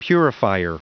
Prononciation du mot purifier en anglais (fichier audio)
Prononciation du mot : purifier